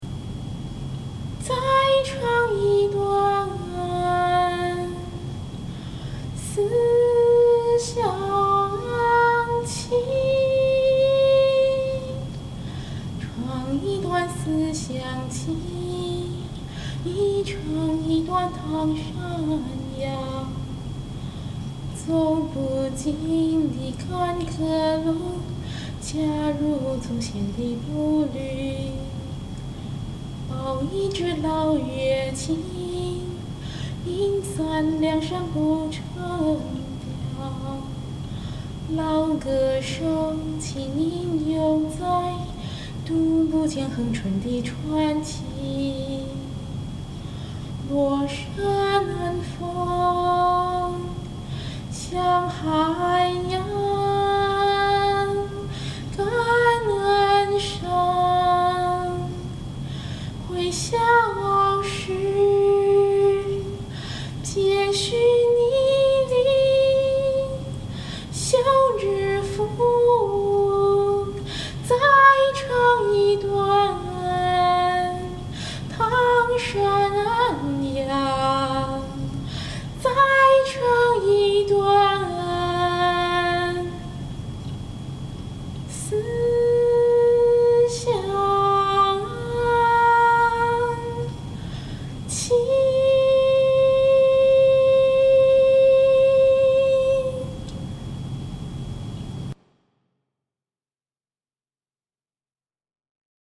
輕鬆的意思是：降 key、亂換氣、亂變奏。 雖然我很弱，可是我還是喜歡在長廊上唱歌。
整首機乎都是假音吧～試試用真音挑戰看看